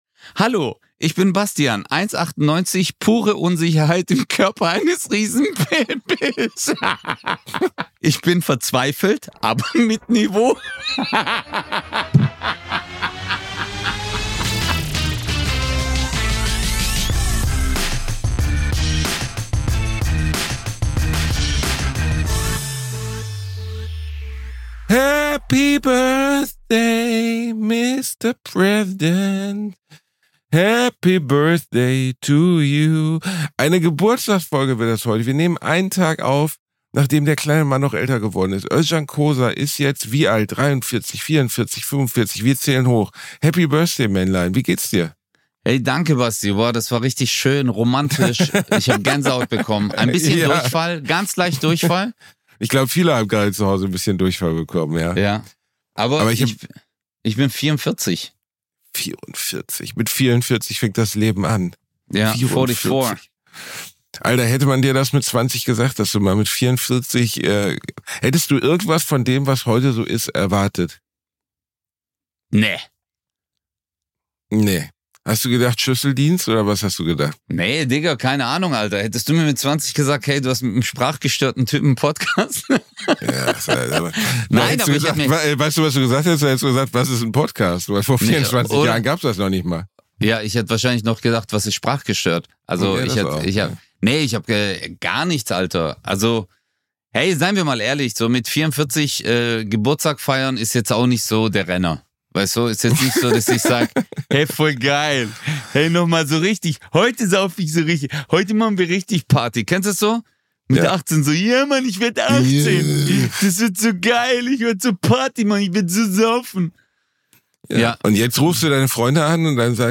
Die Jungs lassen sich von ChatGPT verkuppeln. Ausserdem sprechen sie über die Floating Muschel Erlebnisse und feiern Özcans Geburtstag nach. Frauenstimmen-Parodien sind dabei und Telefonstreiche.